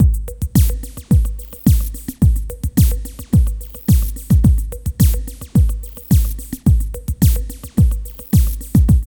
08.5 LOOP.wav